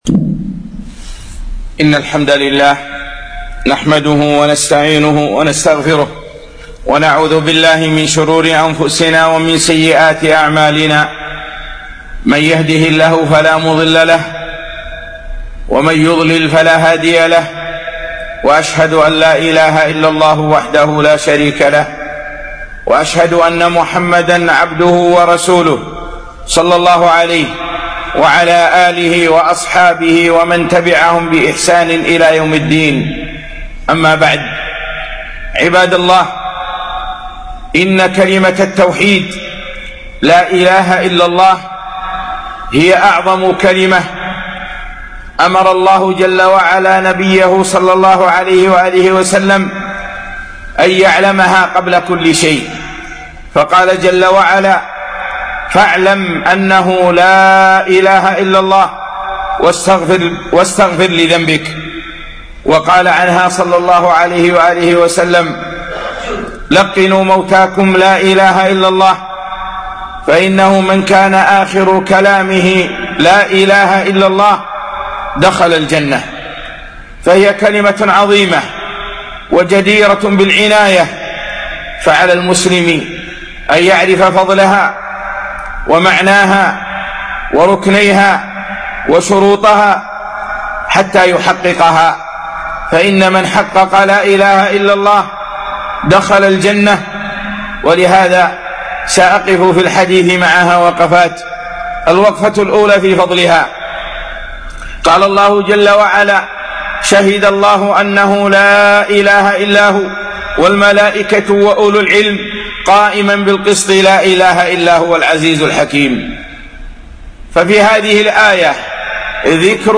كلمة التوحيد - خطبة